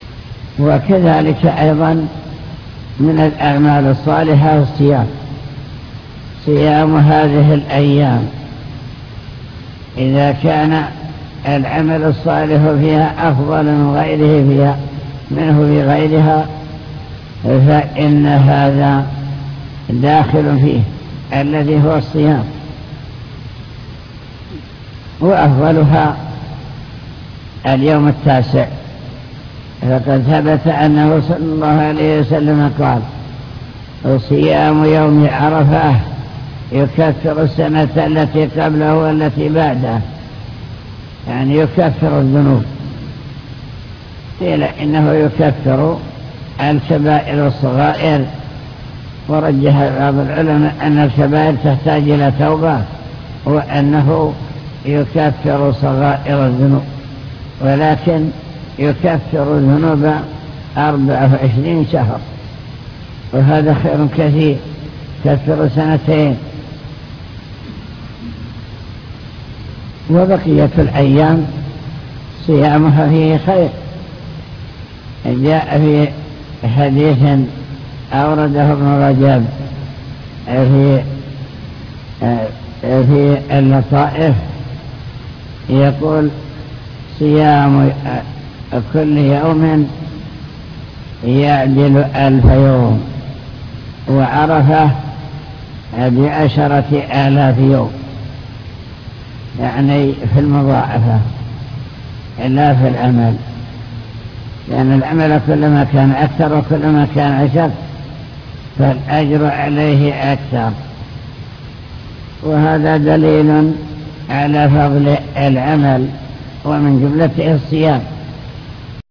المكتبة الصوتية  تسجيلات - لقاءات  اللقاء المفتوح